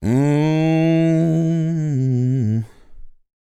MOANIN 103.wav